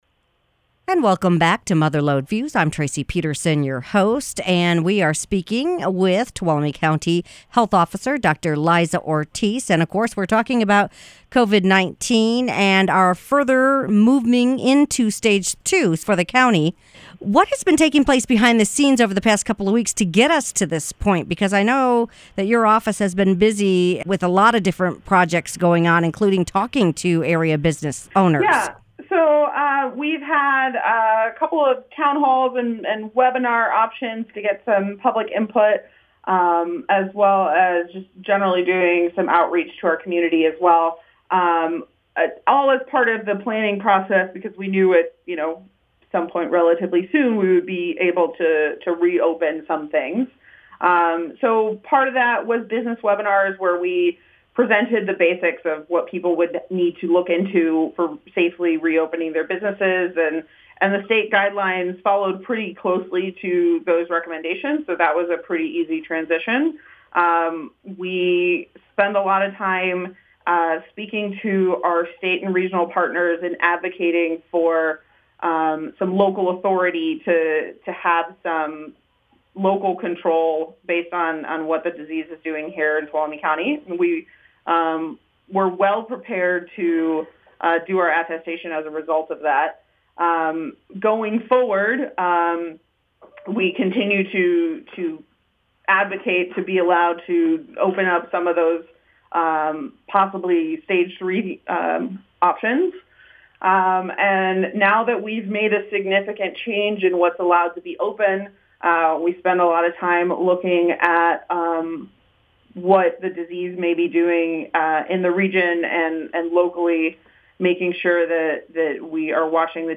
Mother Lode Views featured Tuolumne County Public Health Officer, Dr. Liza Ortiz. She spoke about loosening coronavirus restrictions as Tuolumne County moves through Stage Two. She also gave an update on testing capabilities, and regional efforts looking at reopening federal lands.